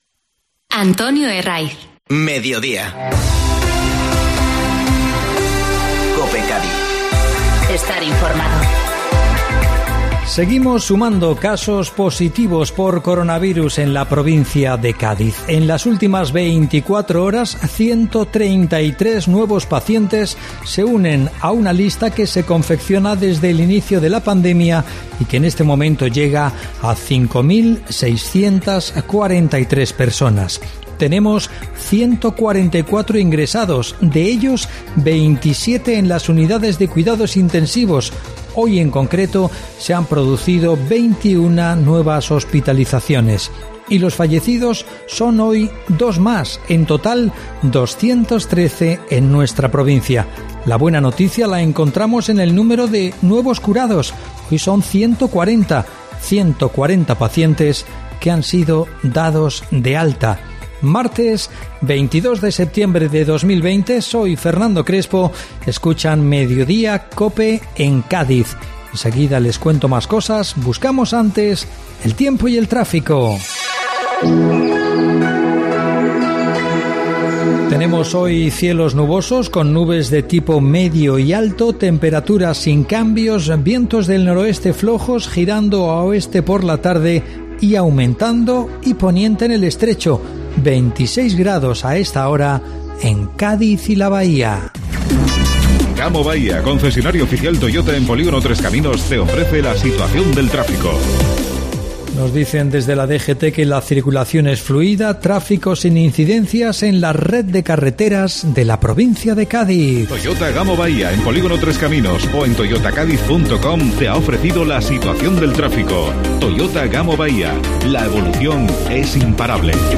Informativo Mediodía COPE Cádiz (22-9-20)